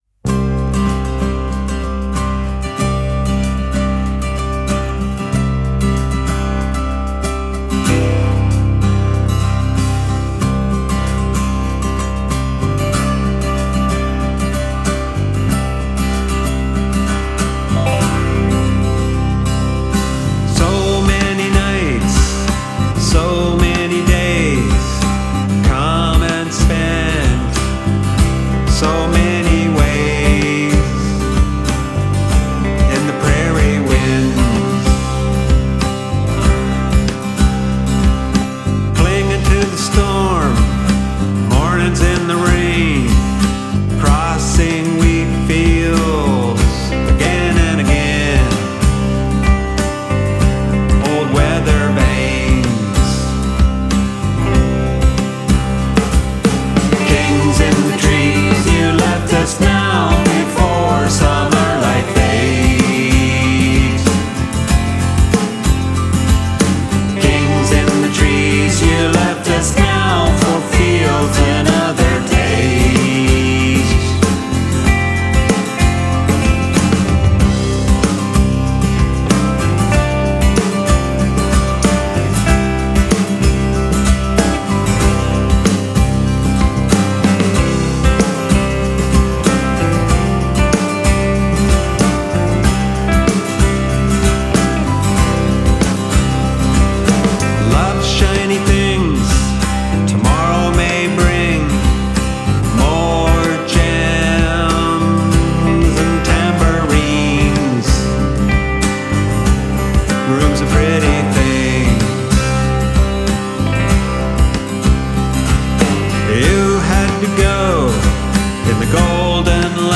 Piano
Bass